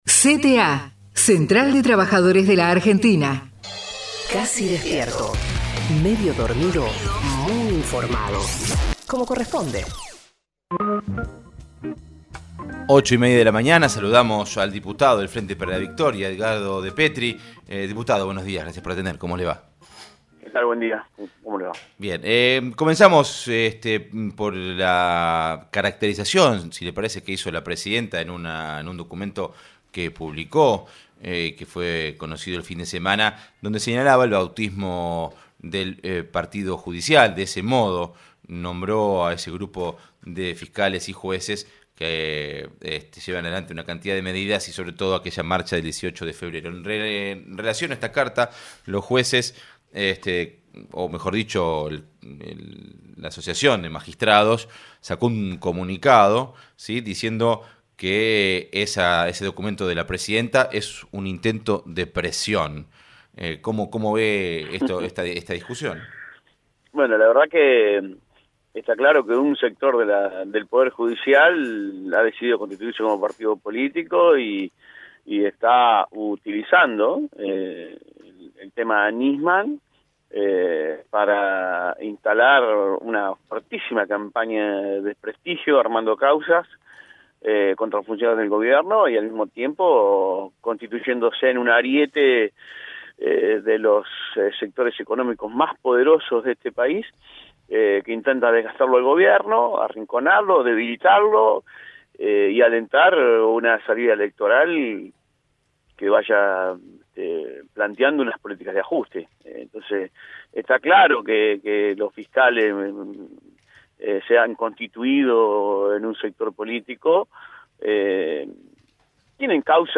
EDGARDO DEPETRI (entrevista) RADIO NACIONAL